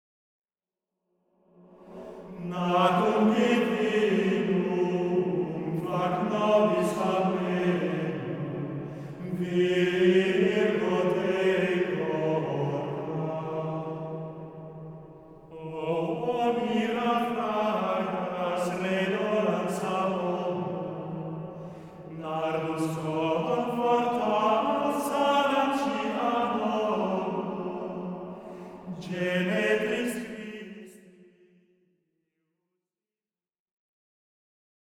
antienne mariale